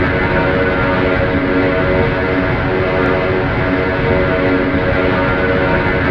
Add external IAE sounds
v2500-buzzDist.wav